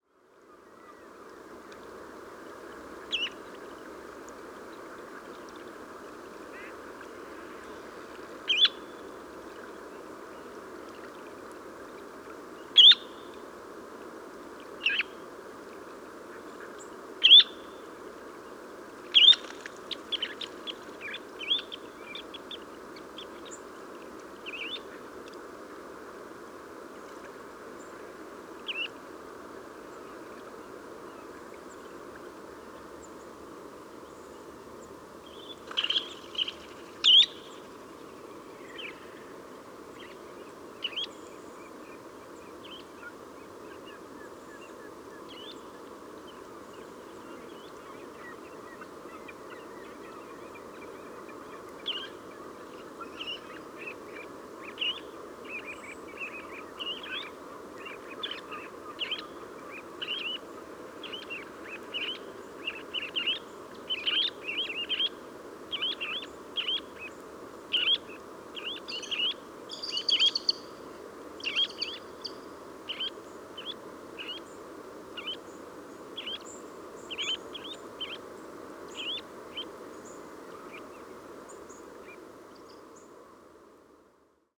Pluvier semipalmé – Charadrius semipalmatus
Écouter les cris de ces petits limicoles aux premières lueurs du jour, observer les oiseaux marins en migration traverser l’horizon, respirer l’air salin de marée basse et sentir le froid annonçant la fin de l’été. Parc nature de Pointe-aux-Outardes, QC, 49°02’32.0″N 68°27’17.7″W. 7 septembre 2018. 5h15. marée basse.